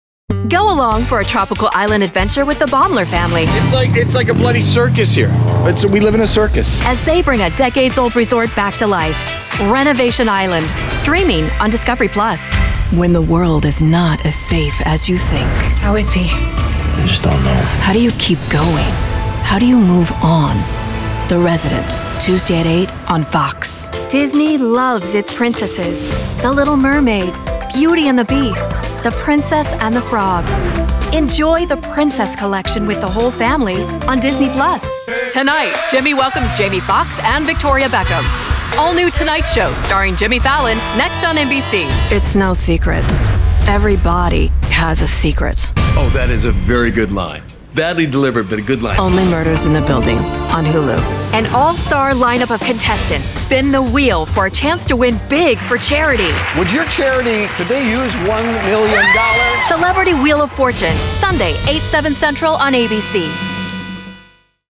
Promo Voiceover